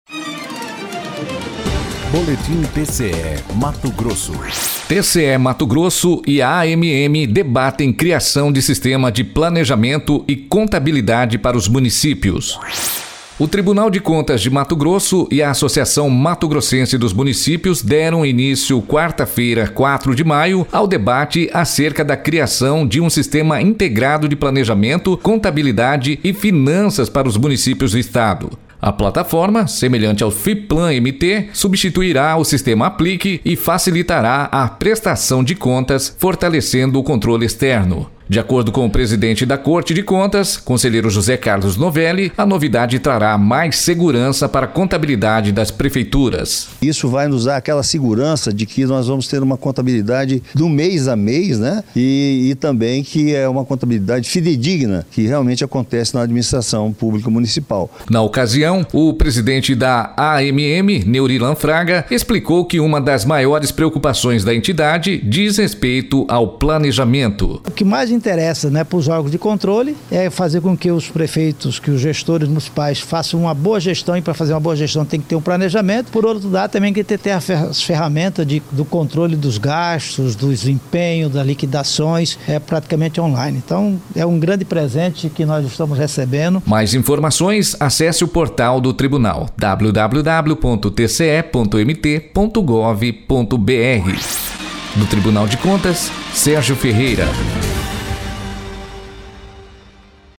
Sonora: José Carlos Novelli – conselheiro presidente do TCE-MT